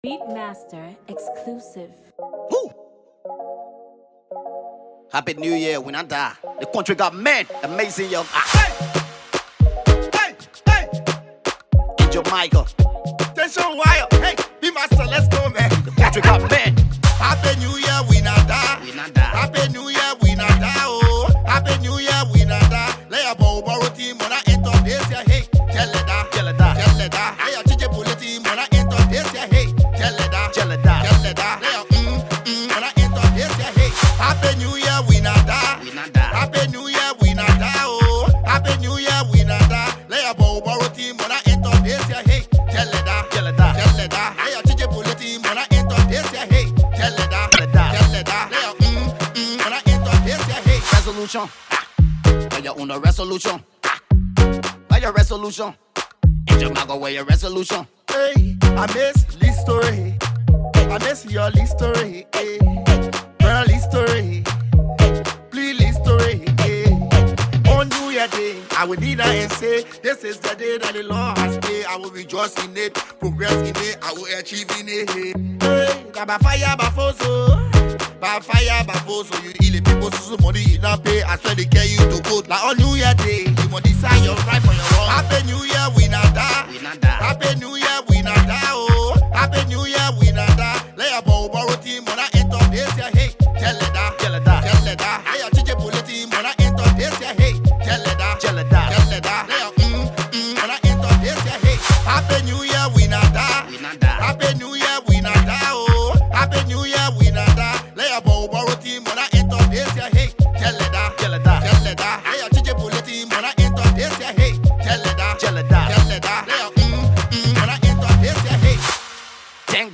Hip-Co